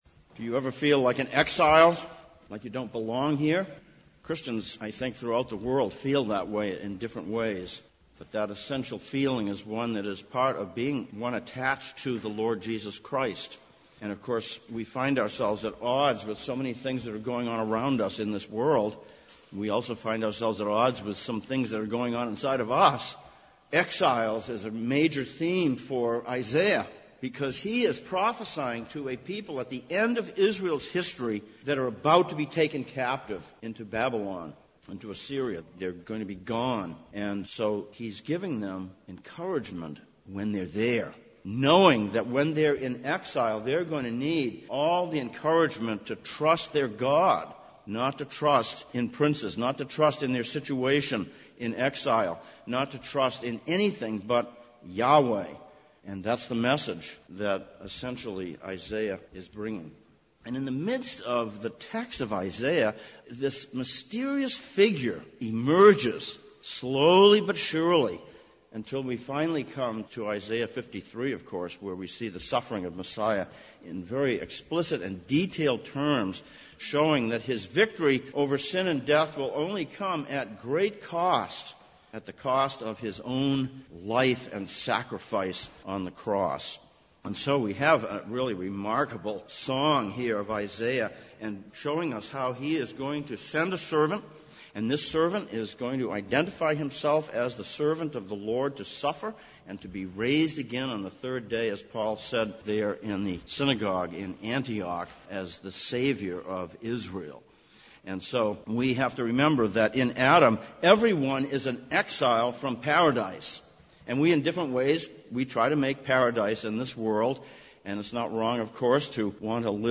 Passage: Isaiah 49:1-13, Acts 13:13-47 Service Type: Sunday Morning